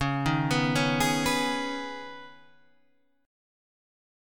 Db7sus2 chord